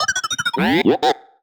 sci-fi_driod_robot_emote_09.wav